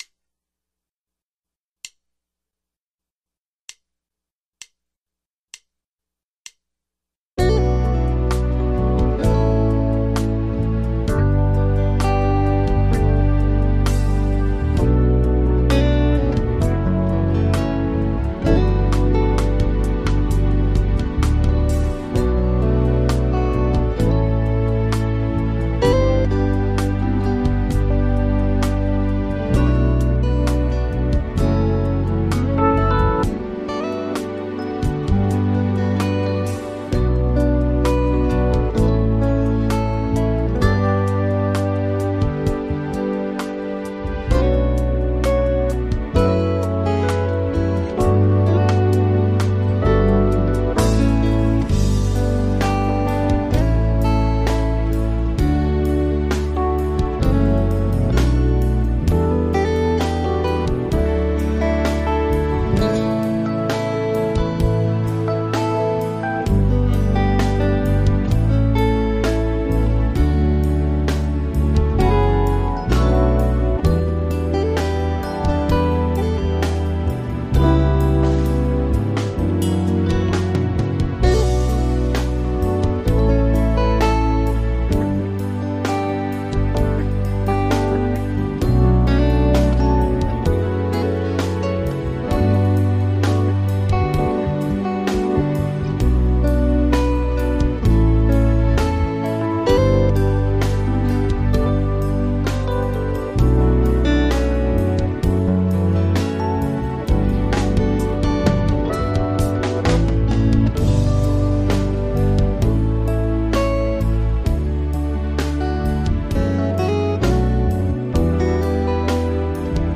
Acompañamento (sen solista)